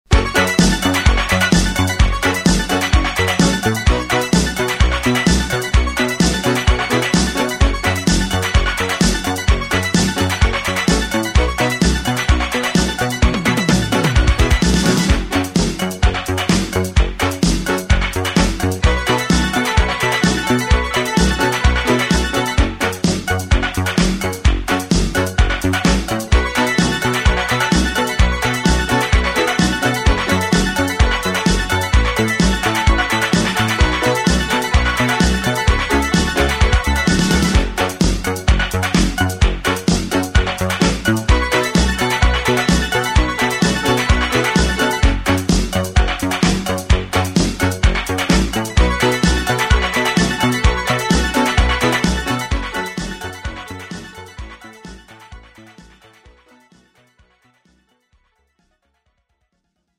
축가 및 결혼식에 최적화된 고품질 MR을 제공합니다!